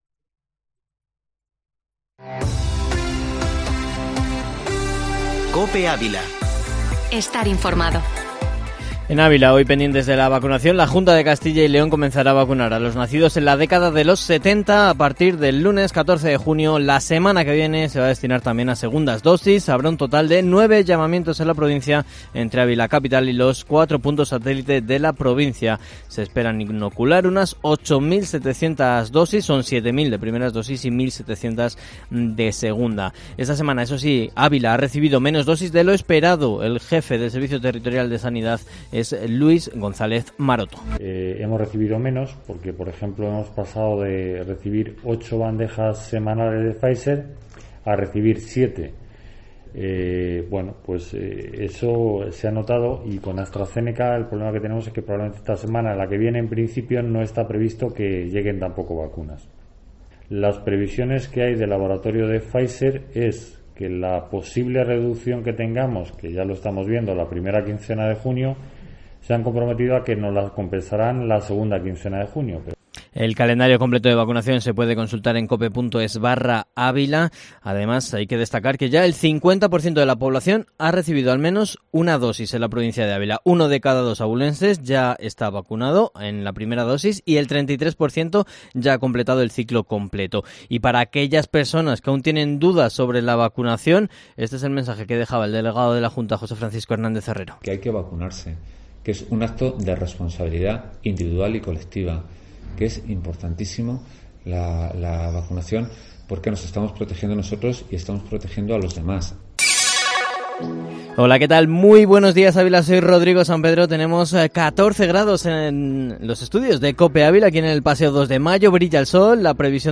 Informativo matinal Herrera en COPE Ávila 10/06/2021